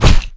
wrench_hit_flesh1.wav